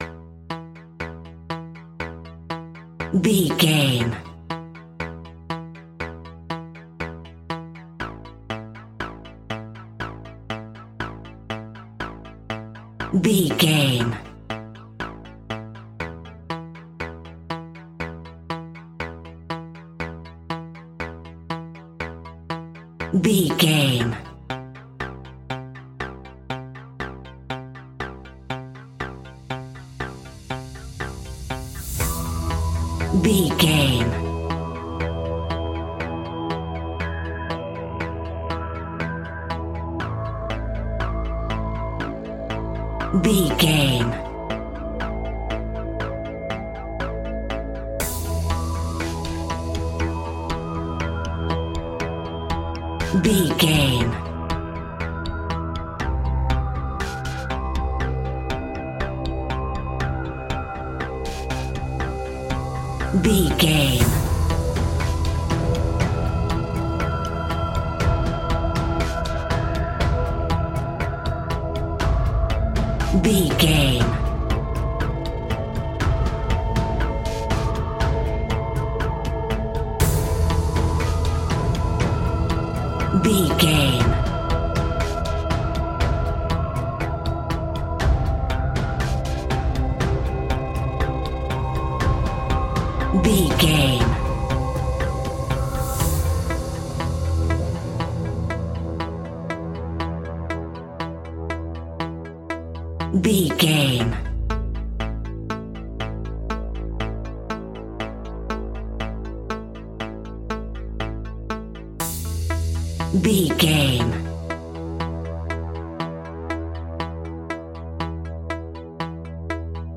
Aeolian/Minor
scary
tension
ominous
dark
haunting
eerie
synthesizer
drum machine
mysterious
ticking
electronic music
Horror Pads
Horror Synths